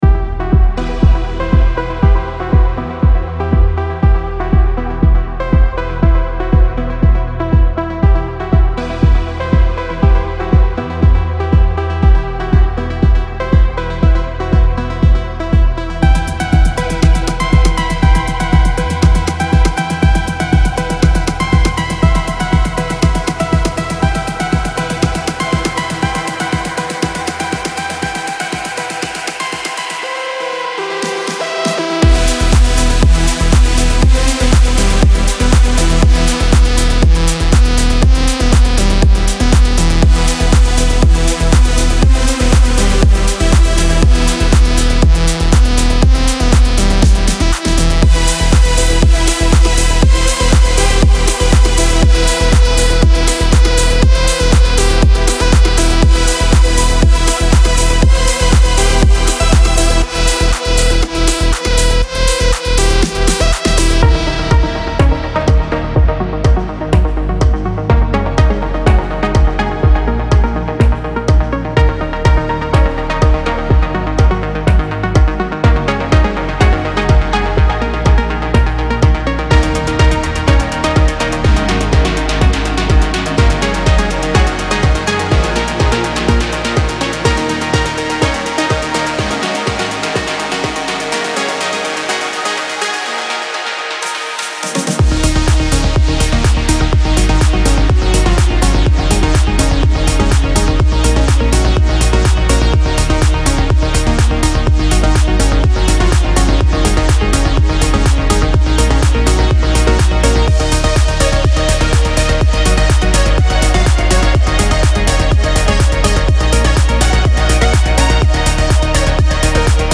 Deep House Future House Progressive House Techno
(Kits BPM 120 – 124).